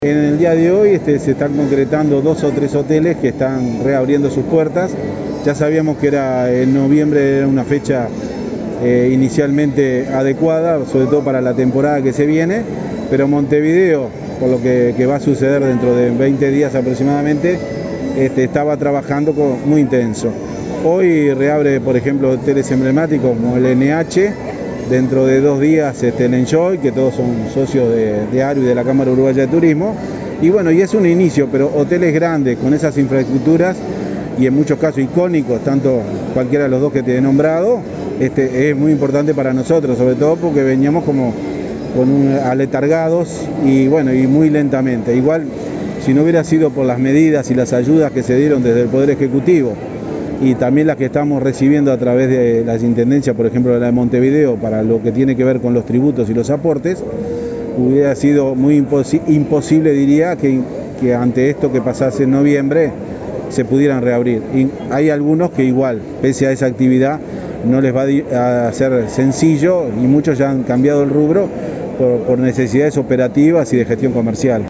en rueda de prensa